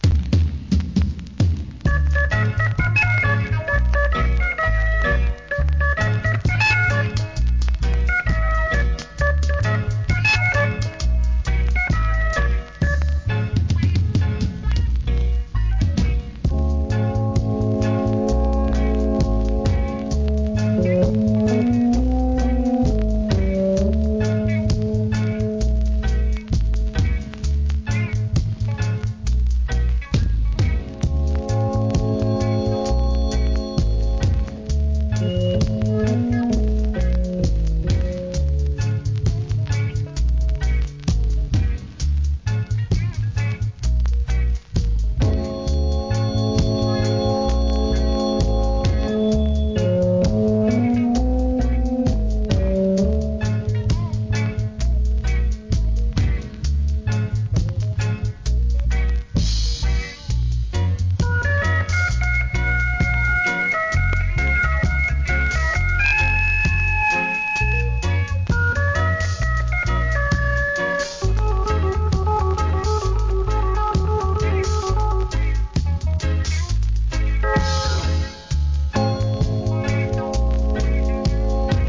REGGAE
ゆったりとしたRHYTHMでのシンセINST.物!!